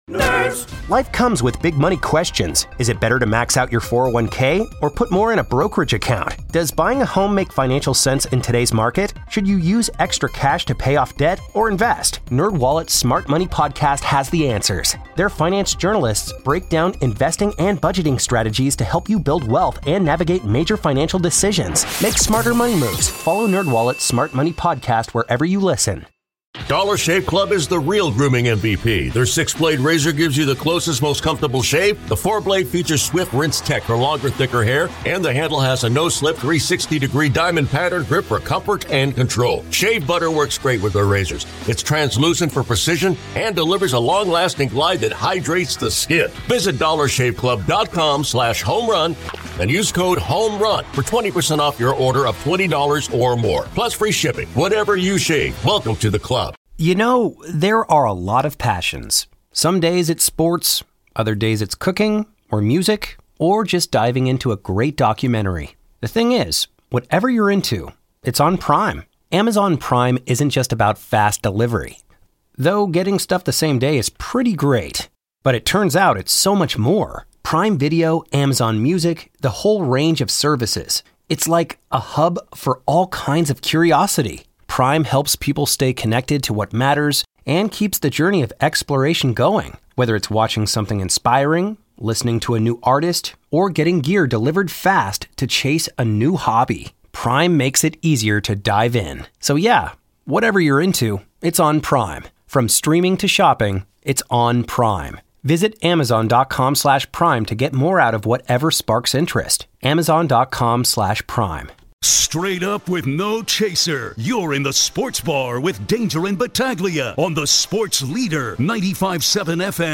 1 Sunderland vs Sheff Utd: Black Cats are back in the Premier League after late Wembley drama! 47:35 Play Pause 7m ago 47:35 Play Pause Play later Play later Lists Like Liked 47:35 David Prutton, Jobi McAnuff, Curtis Davies and Russell Martin are pitch side at Wembley to look back on Sunderland's 2-1 win in the Championship play-off final against Sheffield United.